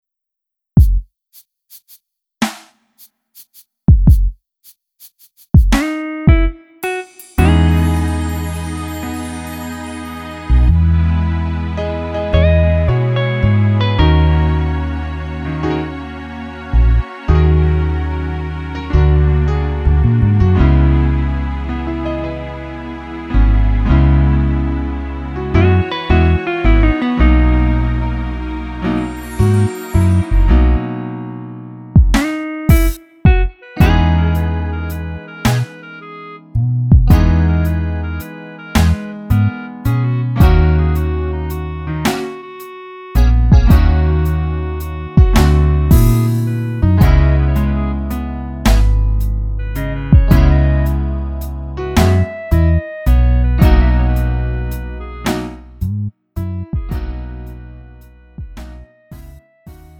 음정 원키 3:41
장르 가요 구분